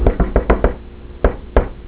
secret knock.
scrtknck.wav